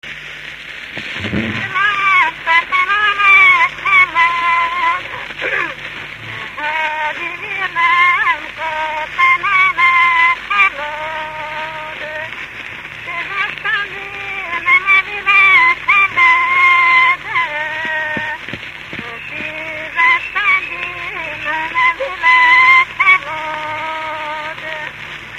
Moldva és Bukovina - Moldva - Trunk
Stílus: 4. Sirató stílusú dallamok